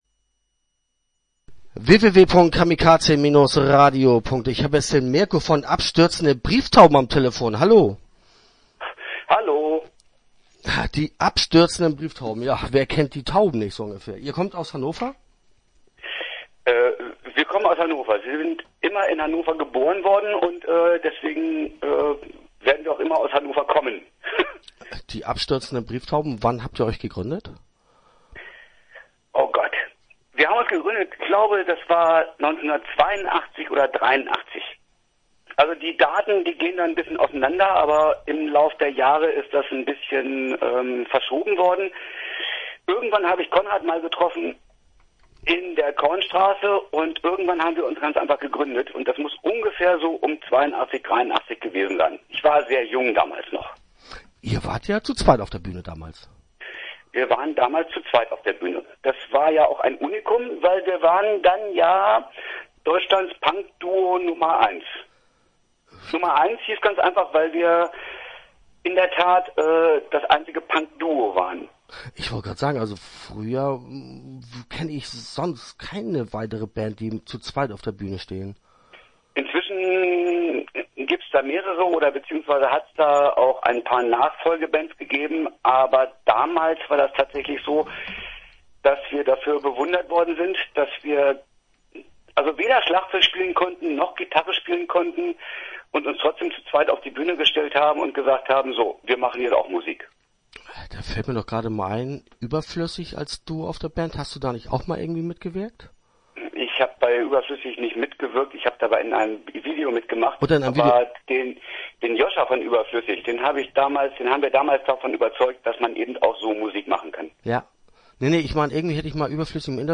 Interview Teil 1 (12:46)